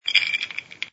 sfx_ice_moving04.wav